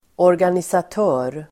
Ladda ner uttalet
Uttal: [årganisat'ö:r]